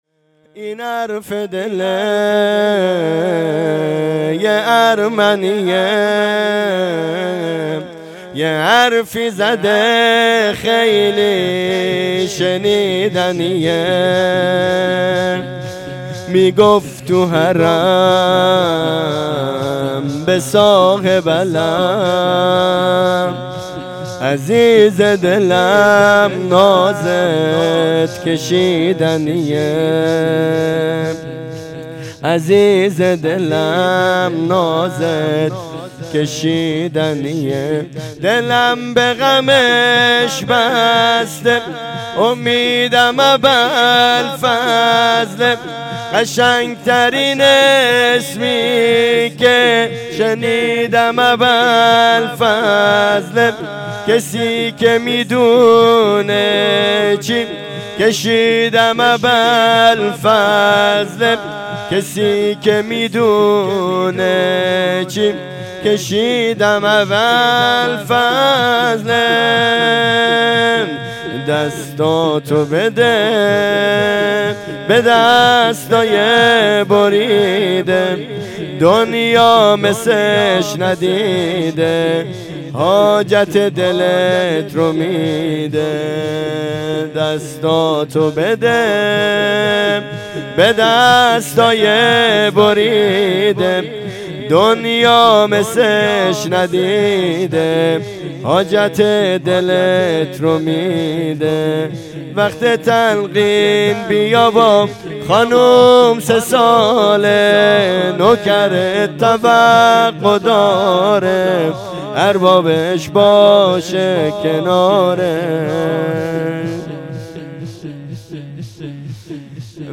ویژه برنامه هفتگی هیئت در ماه مبارک رمضان-وفات حضرت خدیجه1403